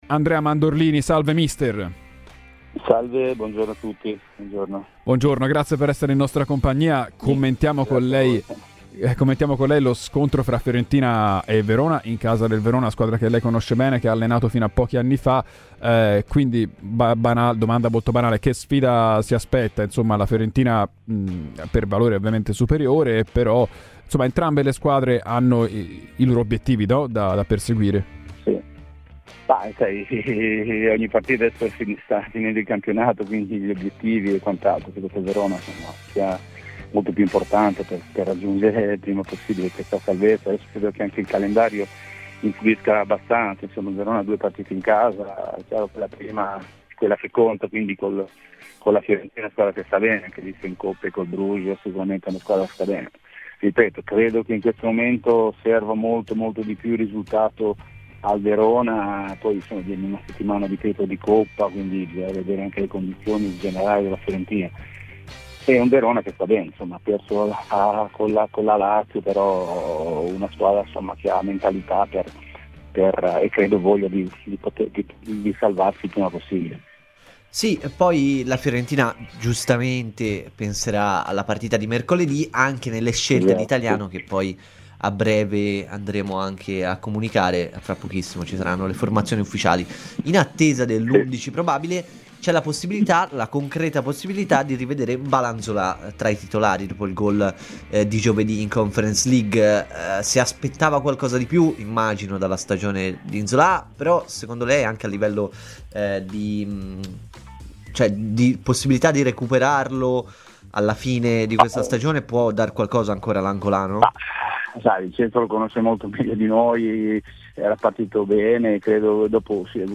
Intervistato da Radio FirenzeViola, durante "Viola Weekend", l'ex allenatore dell'Hellas Verona Andrea Mandorlini ha parlato del match di oggi: "Per l'Hellas oggi credo sia molto più importante rispetto alla Fiorentina, perché la squadra gialloblu vorrà salvarsi il prima possibile.